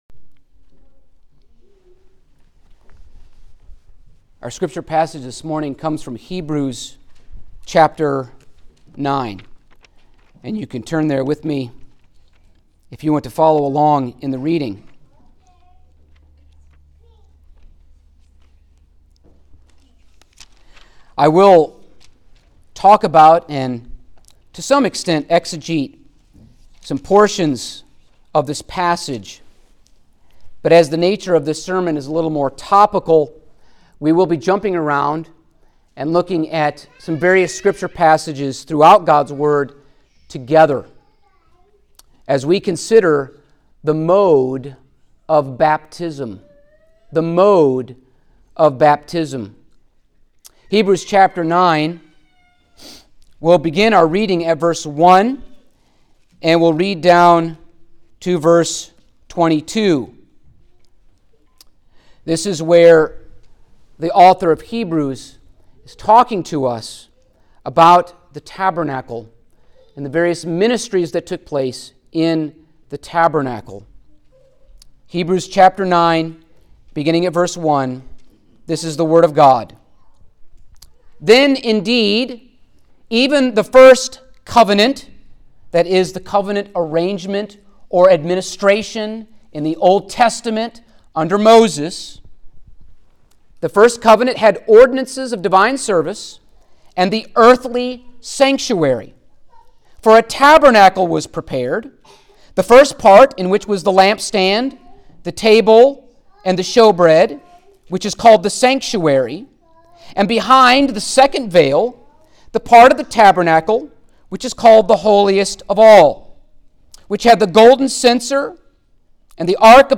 Baptism Sermons Passage: Hebrews 9:1-22 Service Type: Sunday Morning Topics